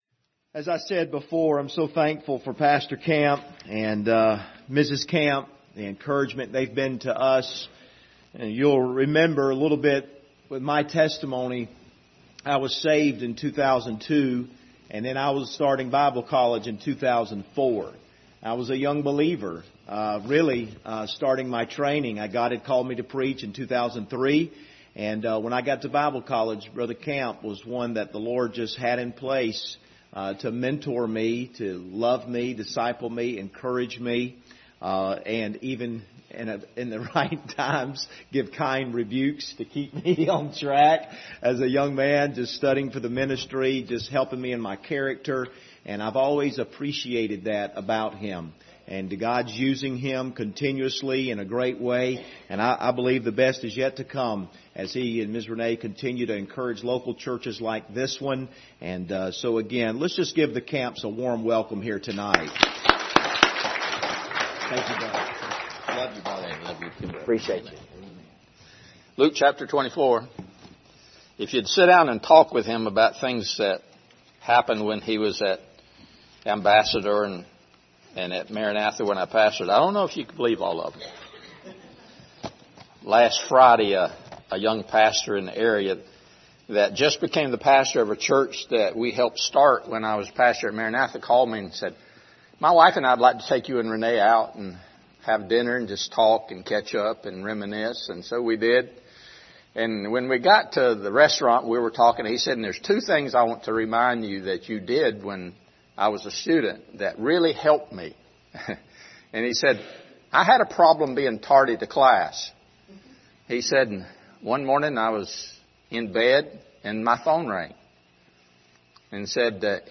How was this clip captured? Luke 24:13-19 Service Type: Sunday Evening « How to Live an Exceptional Life Leadership Lessons in the Life of David Part 1